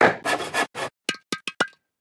CarpenterCraft.wav